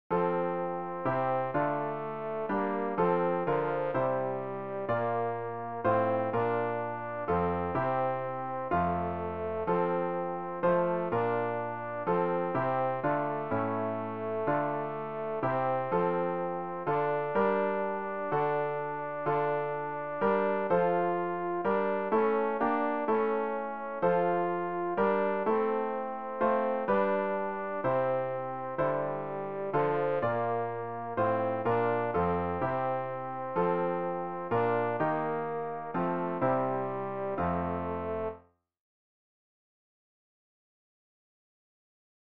Übehilfen für das Erlernen von Liedern
rg-447-jesu-deine-passion-tenor.mp3